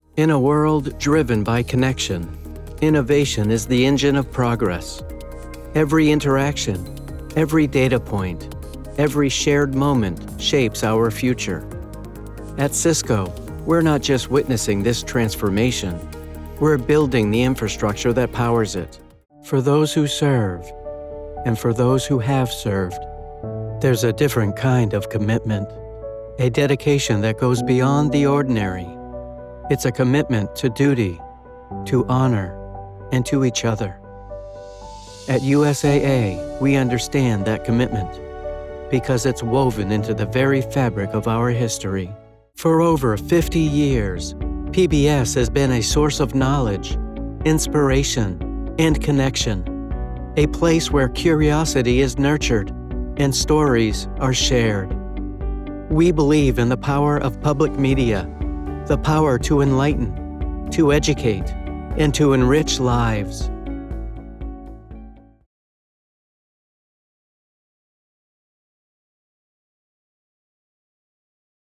Male
I have a conversational tone that is natural, believable, and friendly.
Corporate
Corporate Samples
0812CORPORATE_NARRATION_DEMO.mp3